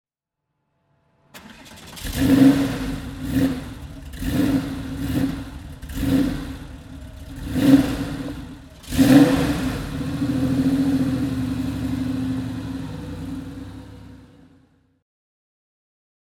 Porsche 964 Carrera 2 Cup (1991) - Starten und Leerlauf